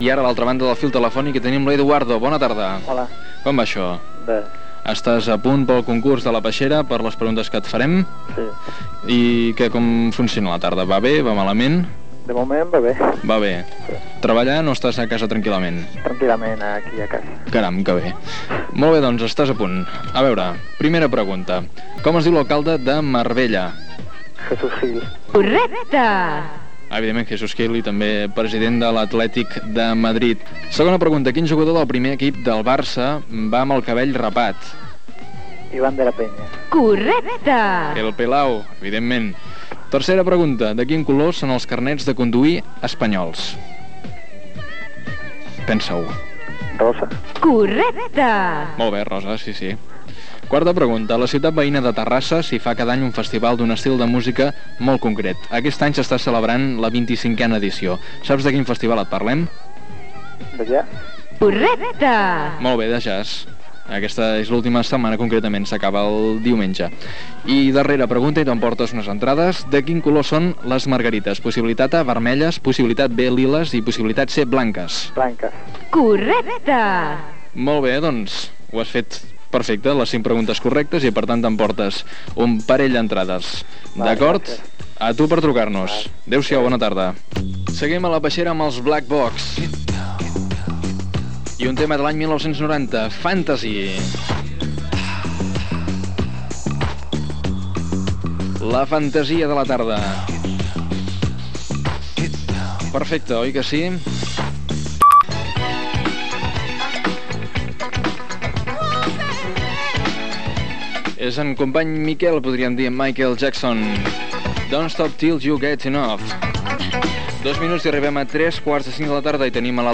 Preguntes del concurs a l'oïdor, tema musical, hora, nou concursant.
Entreteniment
FM